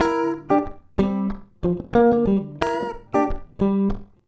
92FUNKY  1.wav